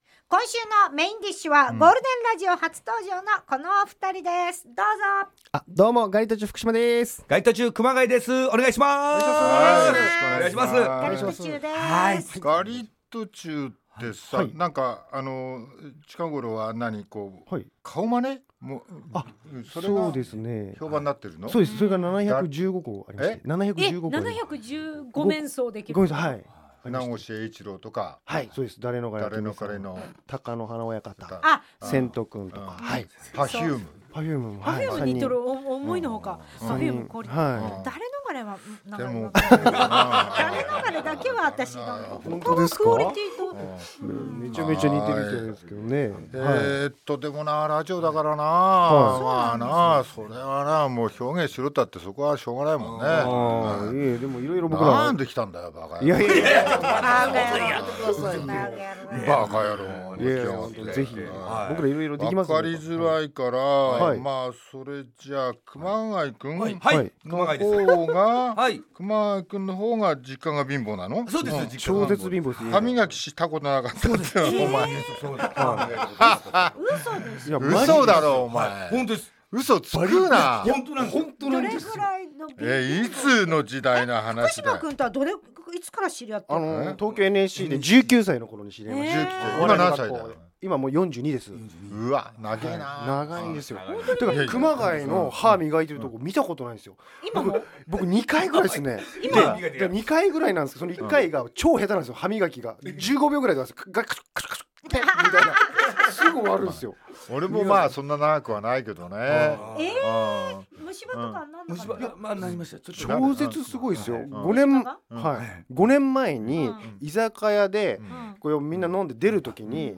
番組のメインを飾るゲストが登場！ 大竹まこと＆各パートナーがお客様のトークを料理します。